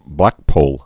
(blăkpōl)